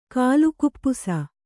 ♪ kālukuppusa